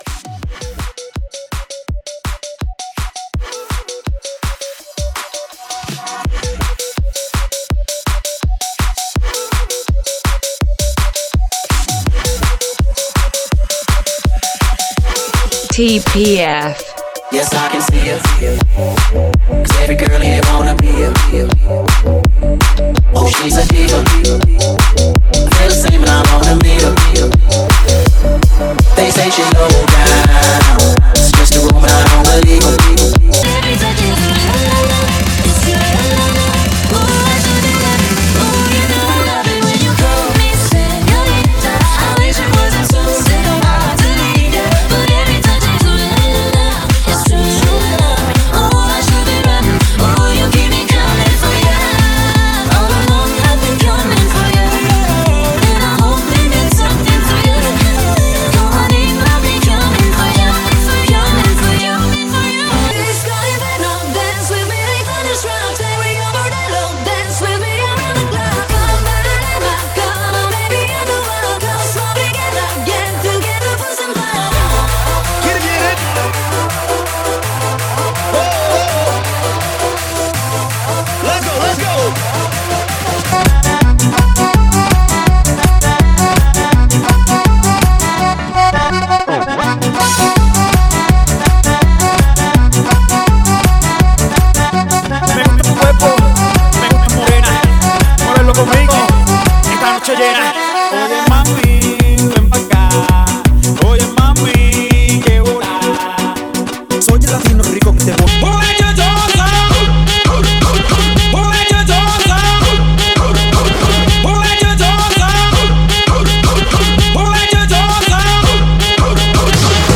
(32 count phrased) 165 BPM
Tempo:      165 BPM